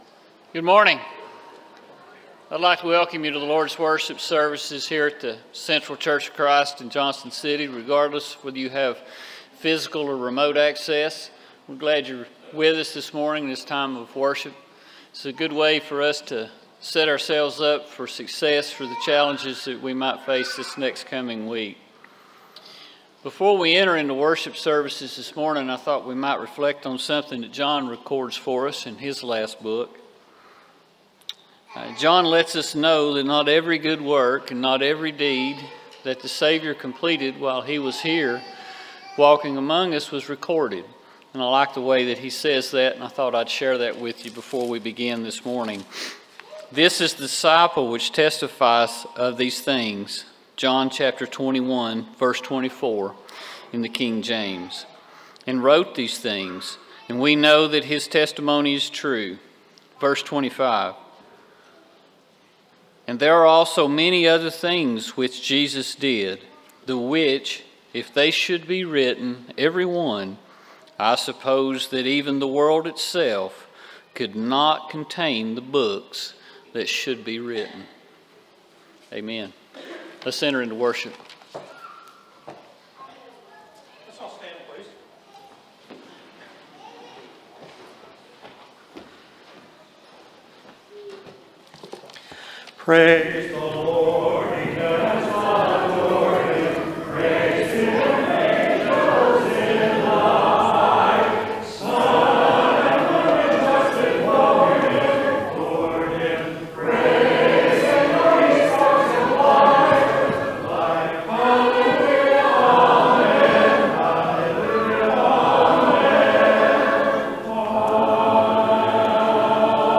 Ecclesiastes 12:14, English Standard Version Series: Sunday AM Service